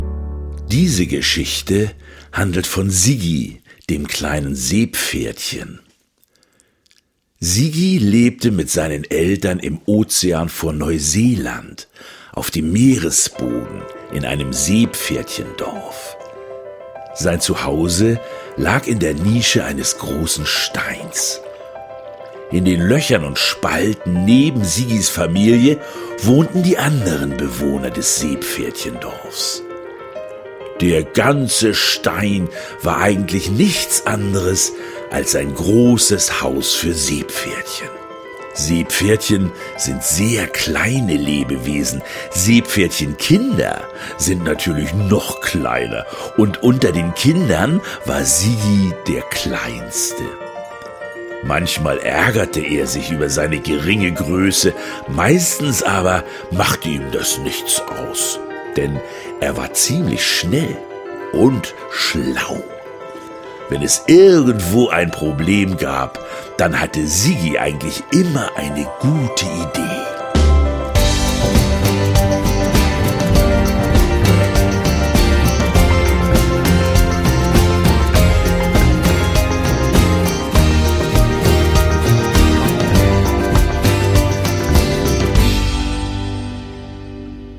Hörbuch-CD oder als Download, mit Liedern zum Mitsingen und Tanzen, 6,90 €
Die Seepferdchen – Hörbuch